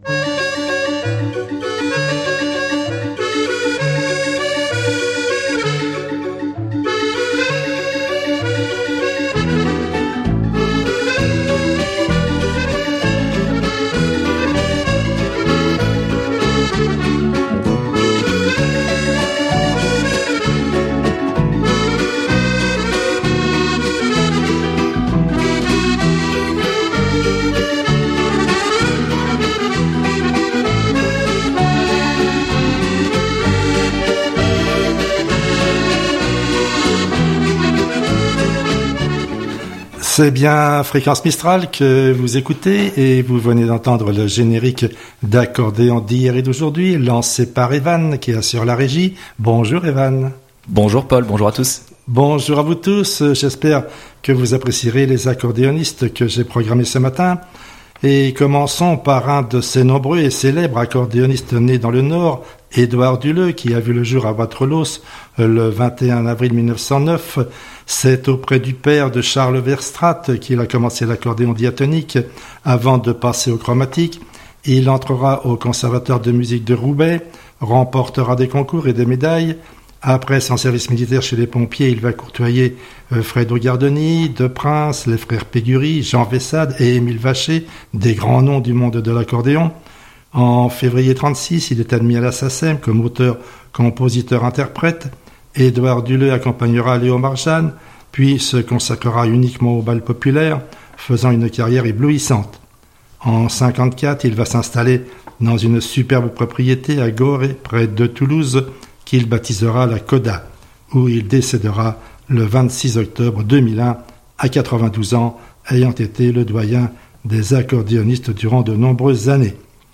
Java du Marquis